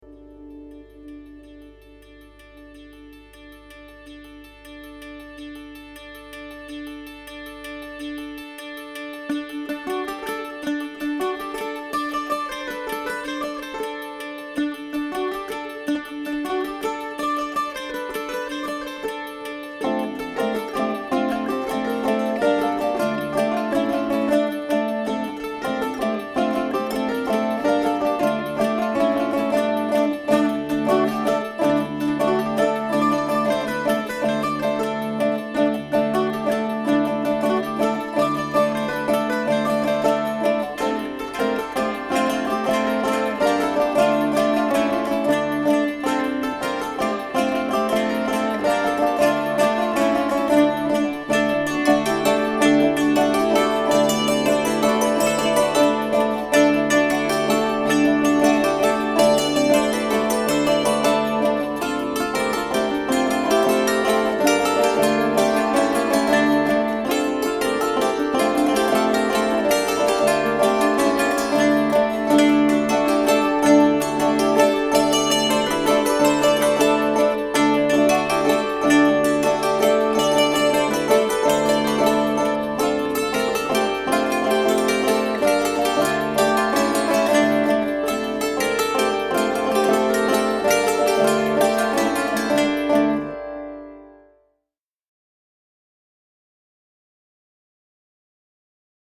Zithermusik und Malerei
„Totalno Mješano“ ist ein Versuch, mit Zithern und Hackbrett ein jüdisches Volkslied zu spielen: „der Rebe hot gehejsn frejlex zajn*“– irgendwie funktioniert es wohl nicht ganz richtig und die Musiker verschlägt es bald von der Ukraine in den hinteren Balkan.
Hackbrett
Zither